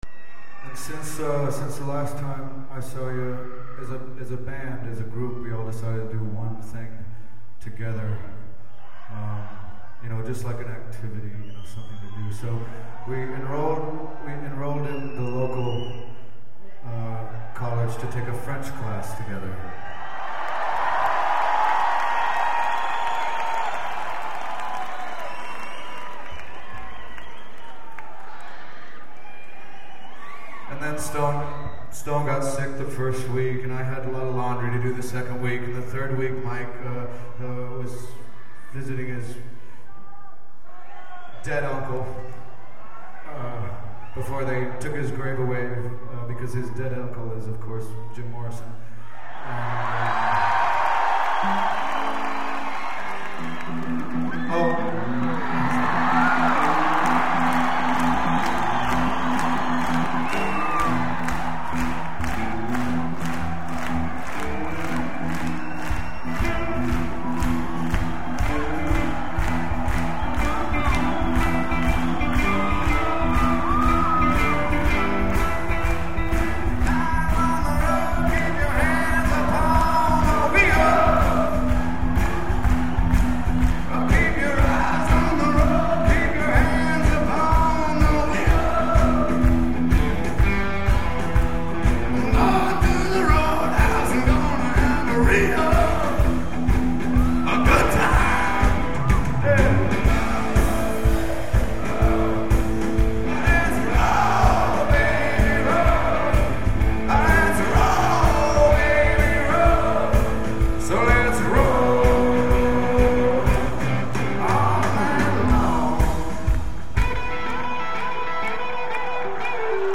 11/07/96 - Le Zenith: Paris, France [115m]